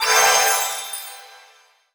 magic_general_item_collect_03.wav